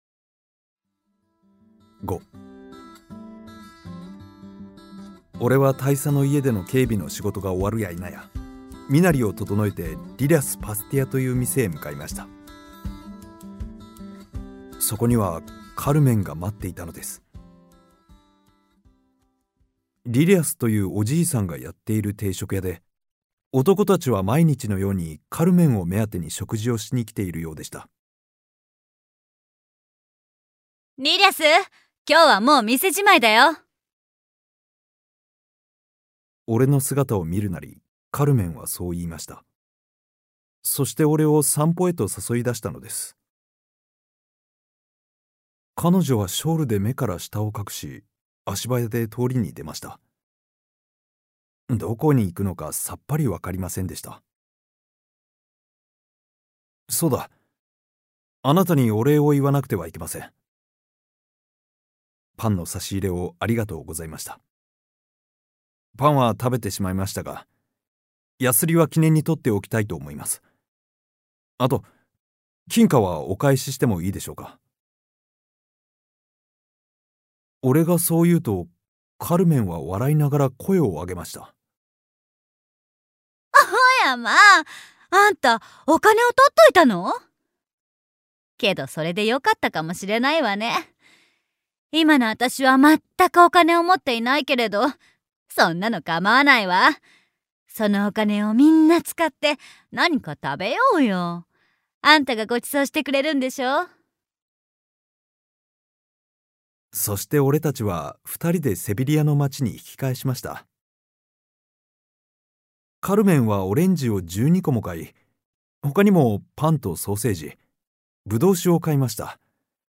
[オーディオブック] カルメン（こどものための聴く名作47）